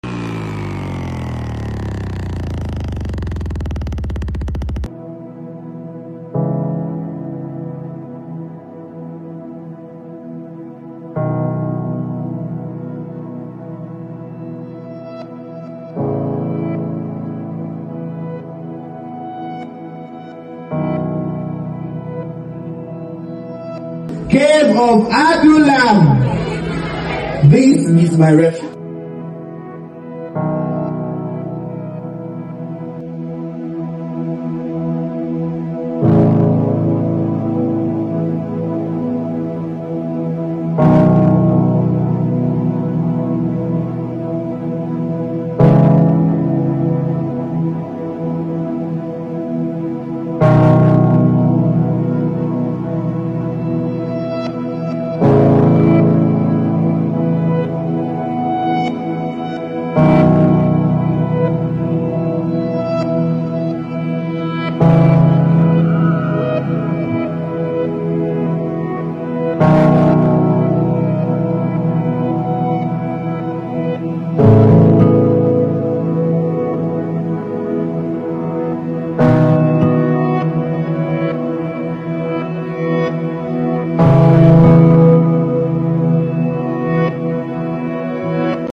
Worship, prayer, and prophetic declarations🔥.Experience sound effects free download
Experience the presence of God through these highlights of the just concluded Cave of Adullam 1.0..where worship meets prophecy